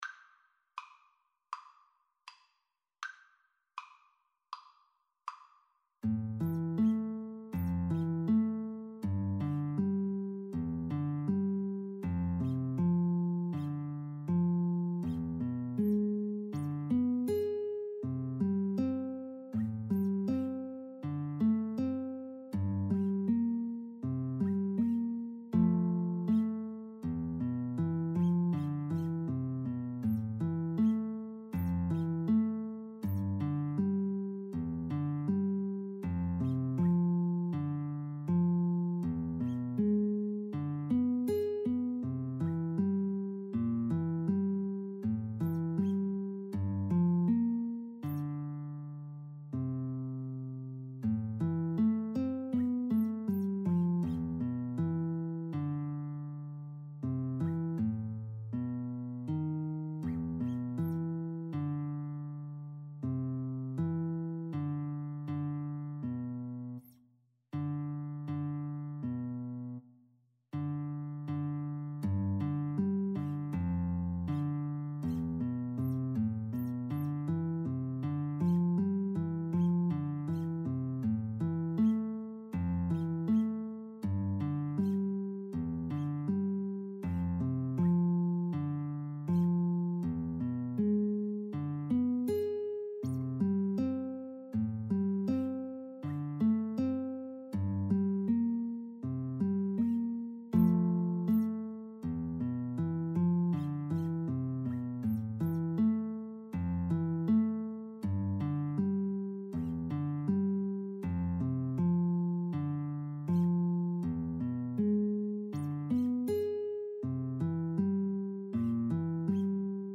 Andante
Classical (View more Classical Guitar-Cello Duet Music)